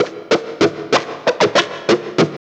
45 GUITAR -L.wav